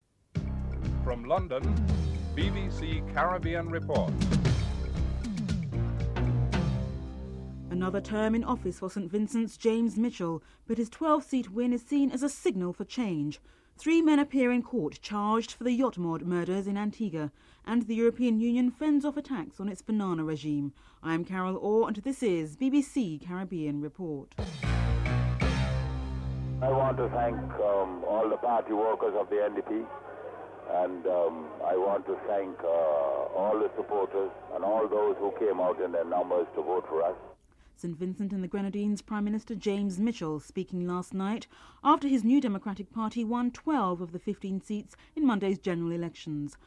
7. Wrap up and theme music (14:40-15:00)